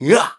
One of Ludwig's voice clips in New Super Mario Bros. Wii